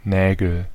Ääntäminen
Ääntäminen Tuntematon aksentti: IPA: /ˈneːɡl̩/ Haettu sana löytyi näillä lähdekielillä: saksa Käännöksiä ei löytynyt valitulle kohdekielelle. Nägel on sanan Nagel monikko.